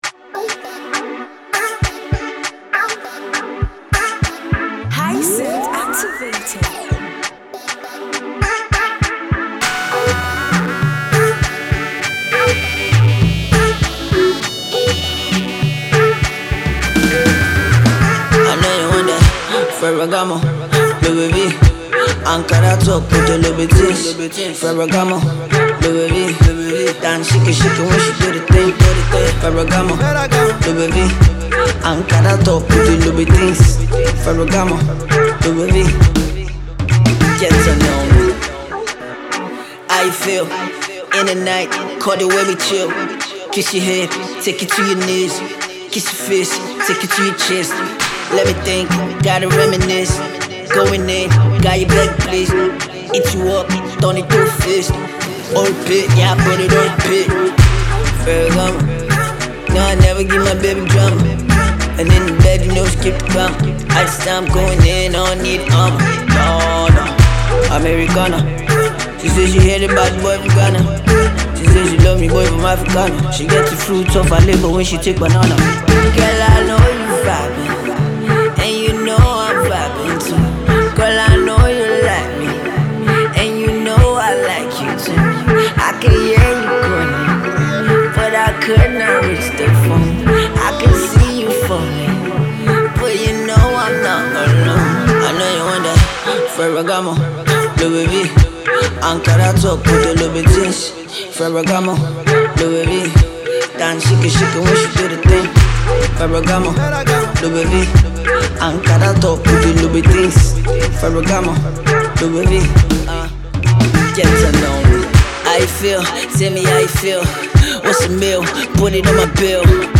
Hip Hop force
The rapper promises to take his fans on a ride in 2018